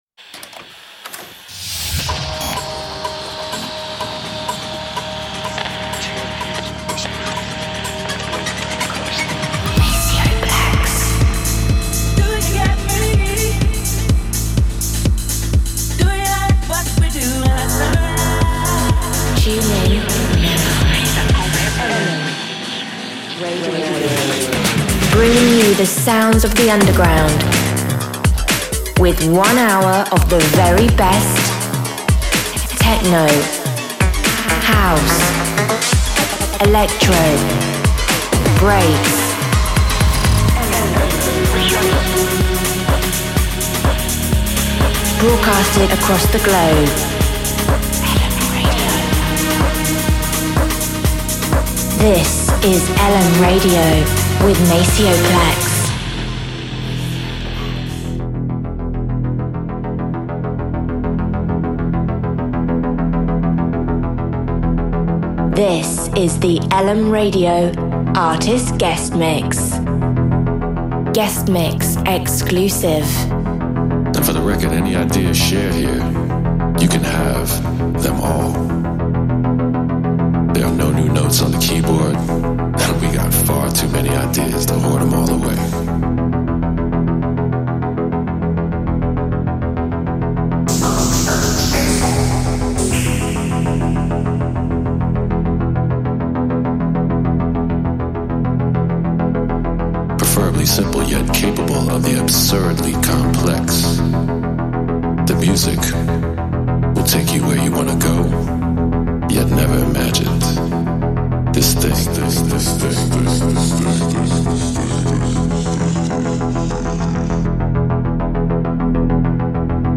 Also find other EDM Livesets, DJ Mixes and